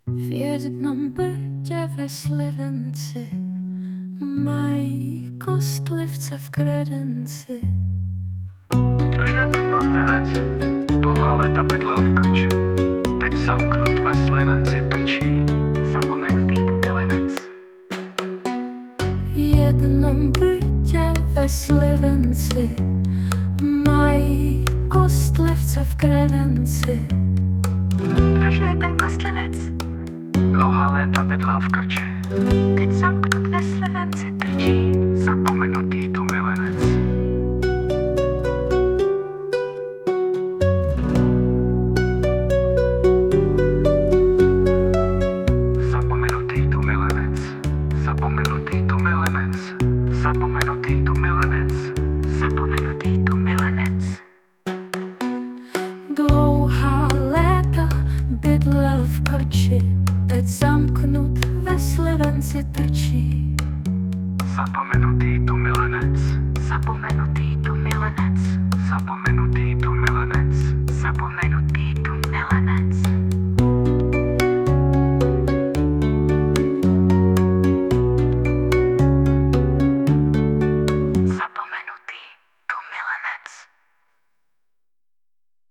hříčka » Humor
* hudba, zpěv: AI